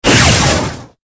resources/phase_5/audio/sfx/lightning_1.ogg at master
lightning_1.ogg